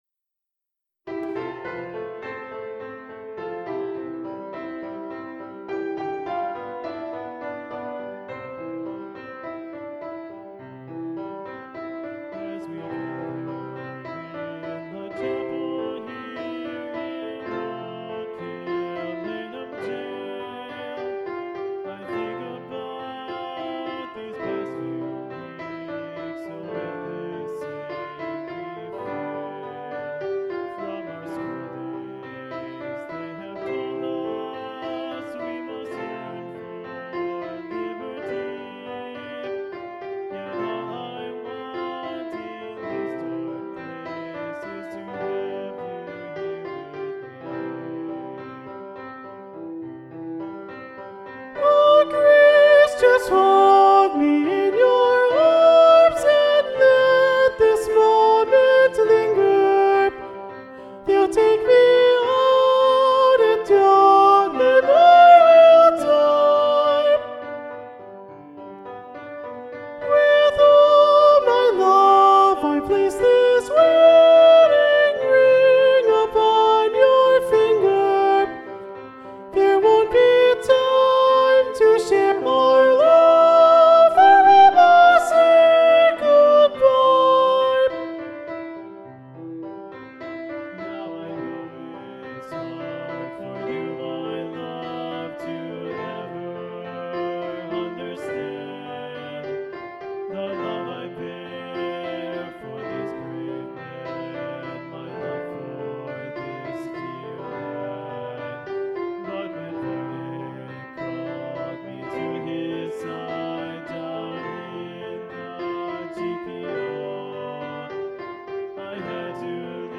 Soprano 1